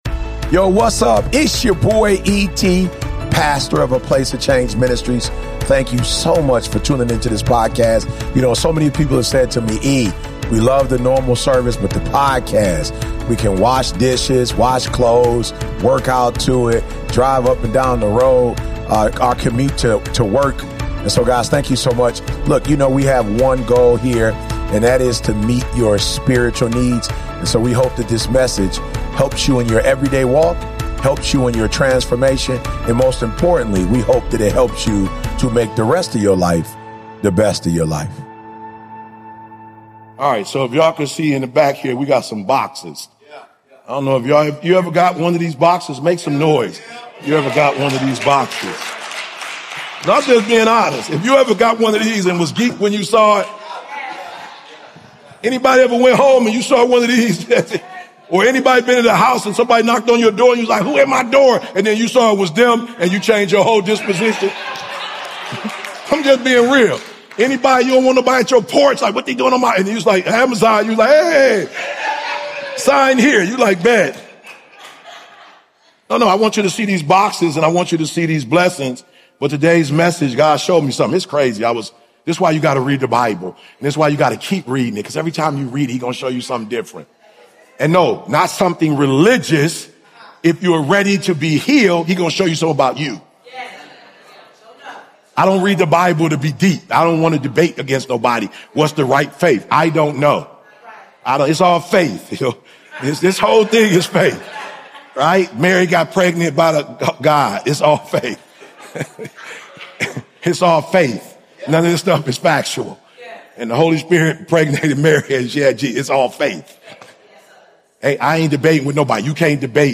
Are you praying for blessings while ignoring God's blueprint? In this powerful sermon